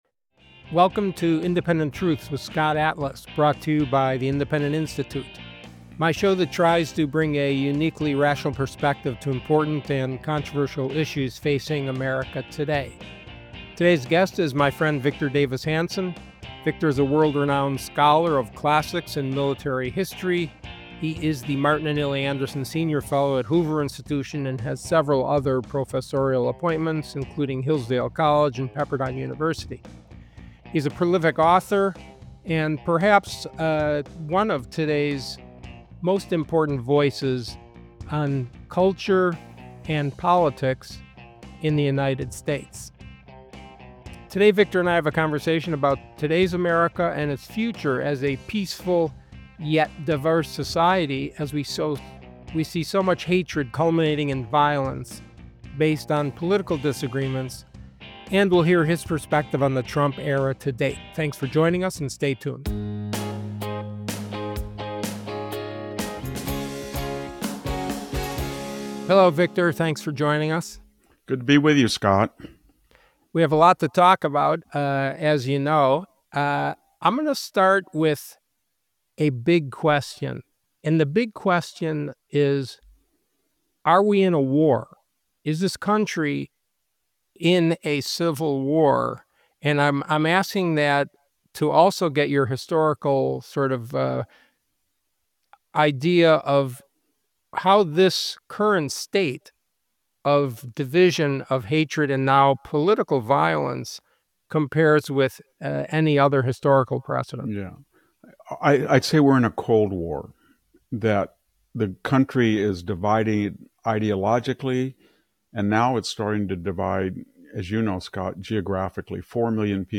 Sponsored by the Independent Institute, the show features Dr. Atlas in conversation with high profile, news-making guests around public health policy, science, civil liberties, censorship and free speech, higher education, the media, and more.